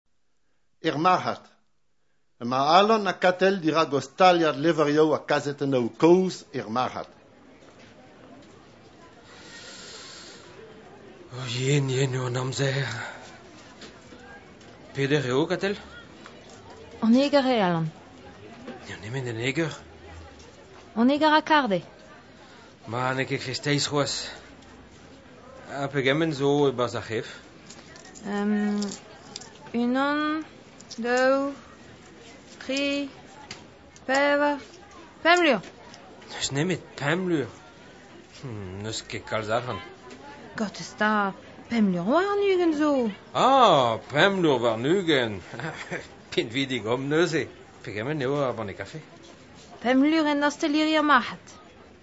Divizioù